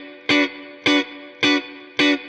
DD_StratChop_105-Bmin.wav